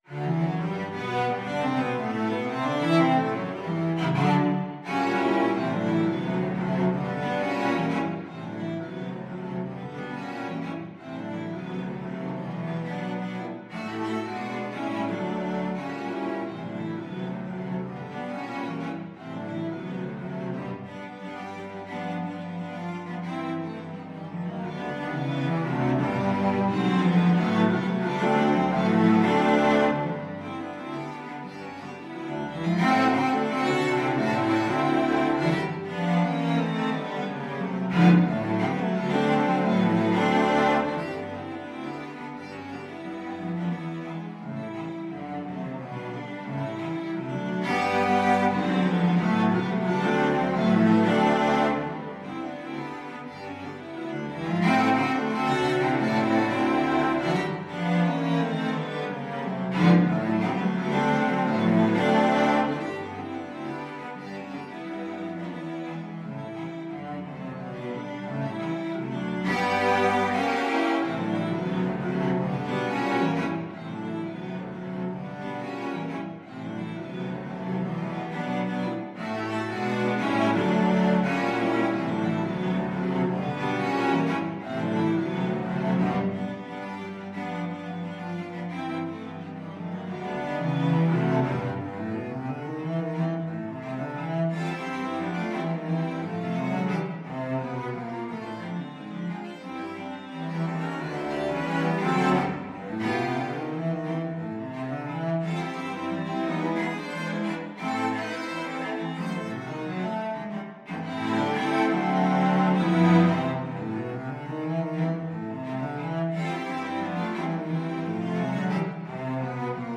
Free Sheet music for Cello Quartet
Cello 1Cello 2Cello 3Cello 4
2/4 (View more 2/4 Music)
G major (Sounding Pitch) (View more G major Music for Cello Quartet )
Slow March Tempo =88
Cello Quartet  (View more Advanced Cello Quartet Music)
Classical (View more Classical Cello Quartet Music)